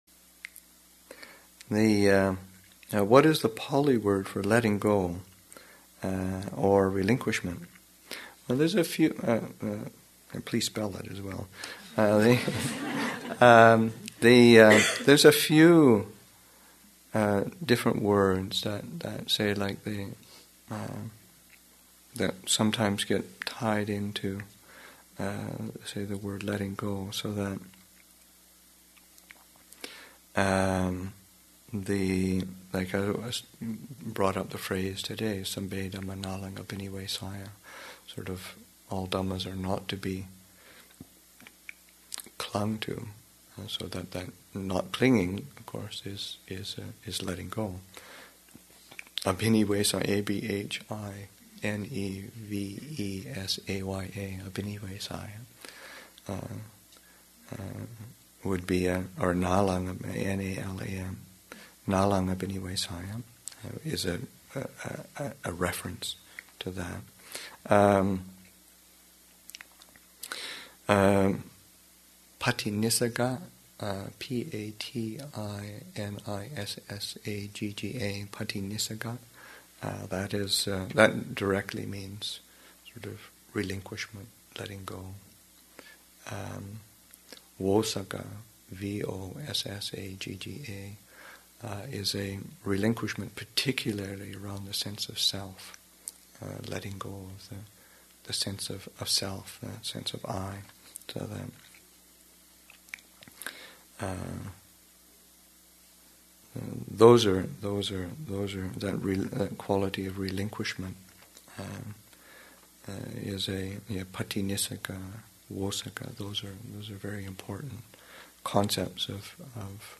Metta Retreat, Session 4 – Sep. 12, 2008